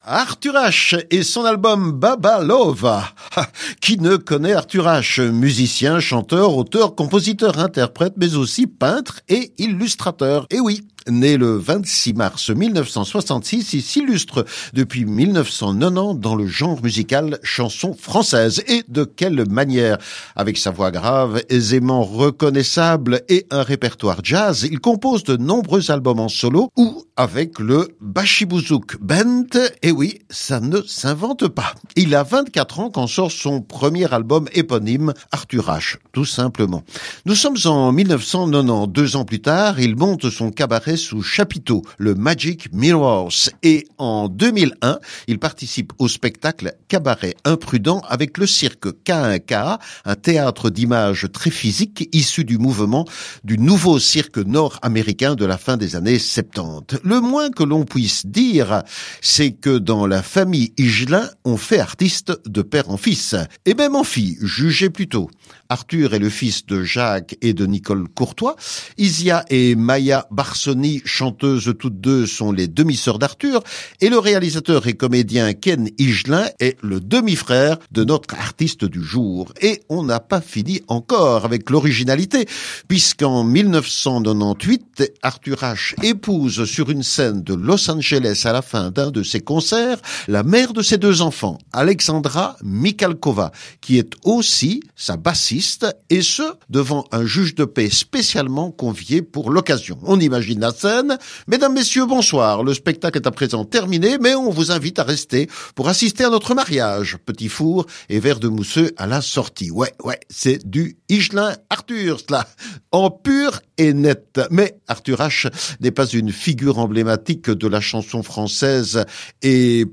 La musique est là, sobre, élégante, moderne, classieuse.
guitare, une section rythmique infernale
Enregistrement à la cosaque, sur bandes analogiques …